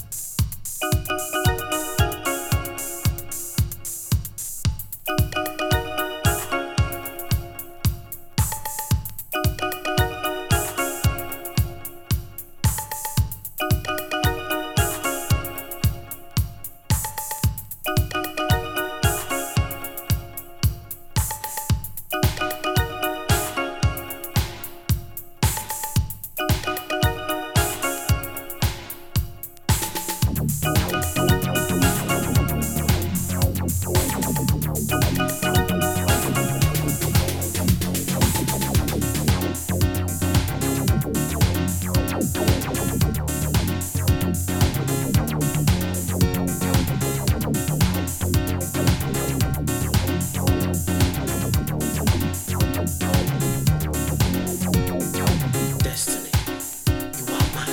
ElectroとHouseのいいとこをMixさせたようなサウンド。Acid風味も○♪Label